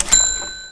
ching.wav